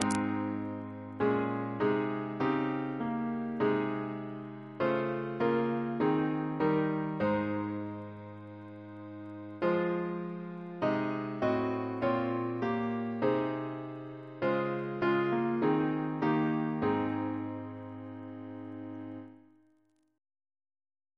Double chant in A♭ Composer: Edgar Day (1891-1983) Reference psalters: CWP: 103; RSCM: 41